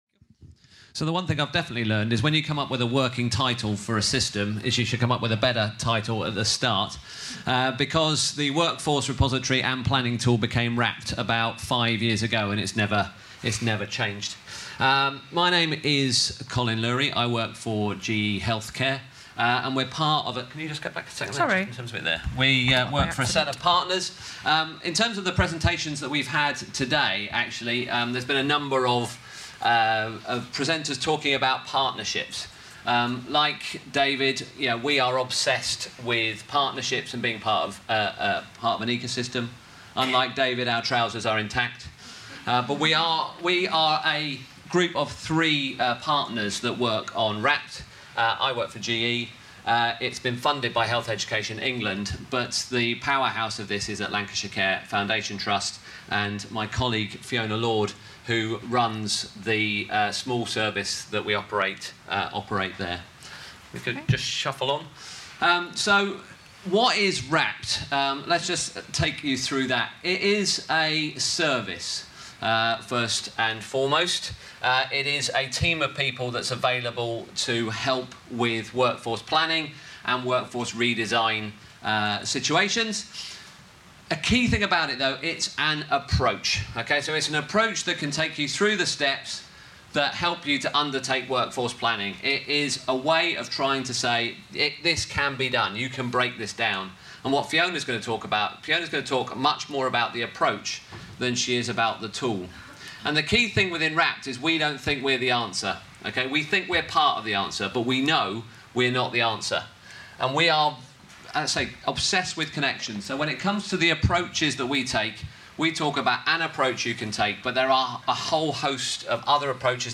Recorded on 27 March 2019 during Ecosystem 18 at Haydock Park Racecourse.